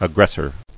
[ag·gres·sor]